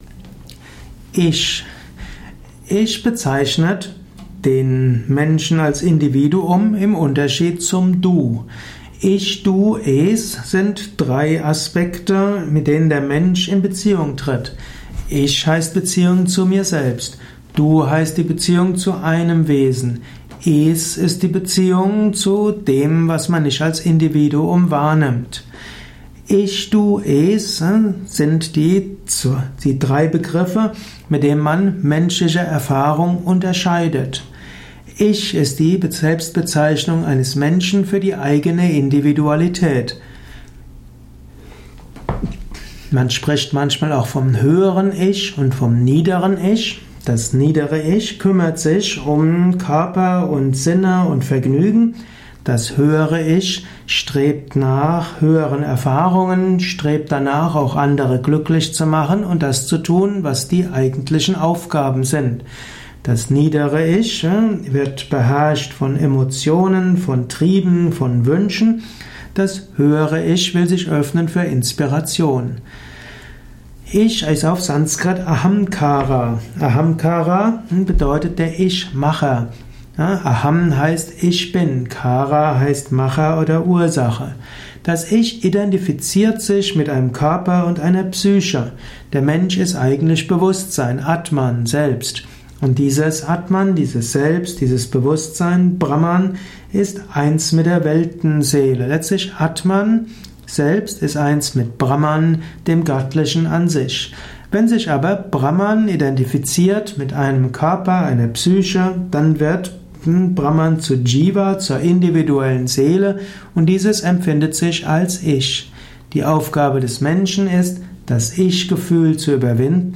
Ein Vortrag über das Ich, Teil des Yoga Vidya Lexikons der Tugenden, Persönlichkeit und Ethik.